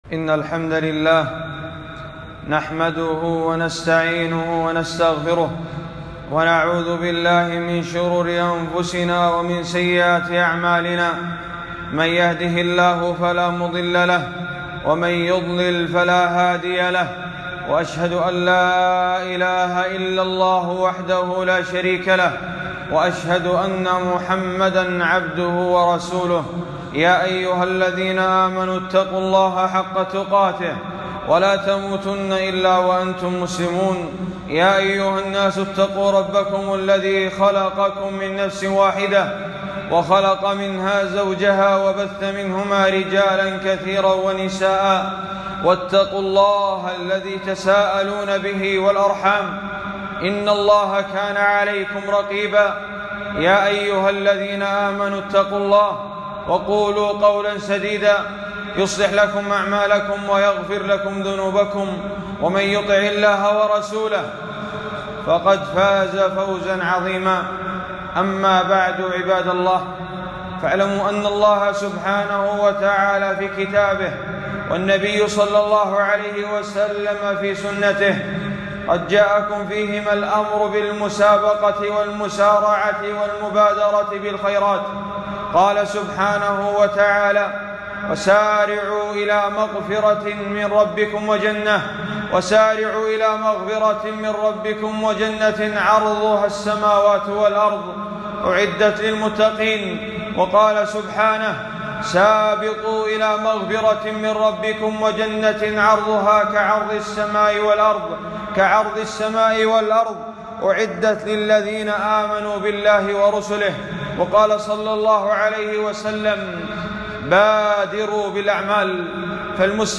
خطبة - لو يعلمون ما في التهجير لاستبقوا إليه